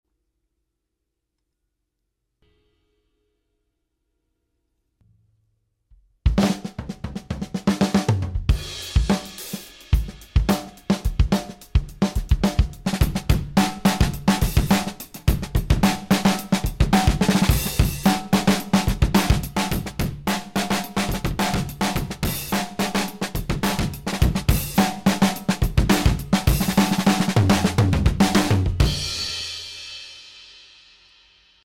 When I record drums I like to have one or two mics that are heavily processed, usually distortion, reverb, or delay. These mics can become the whole drum sound or just be sprinkled in to taste. Here is an example using the JHS Colour box for an exciting distorted sound.